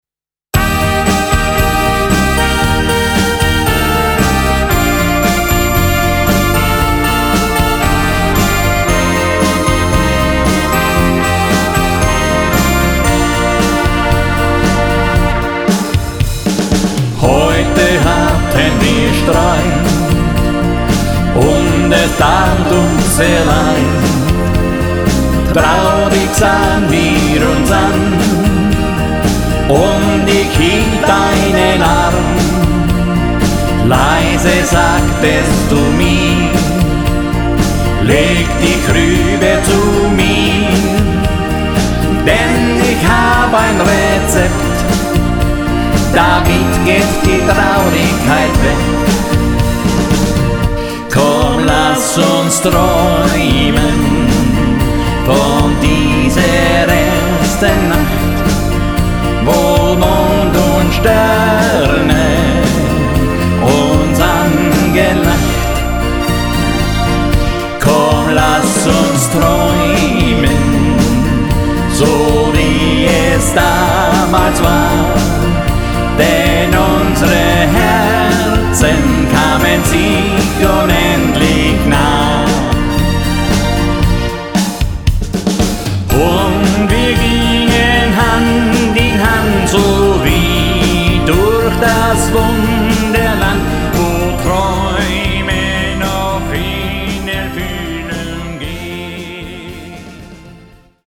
Tanz- und Unterhaltungsmusik